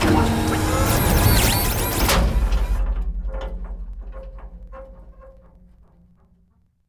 DockingBayDock.wav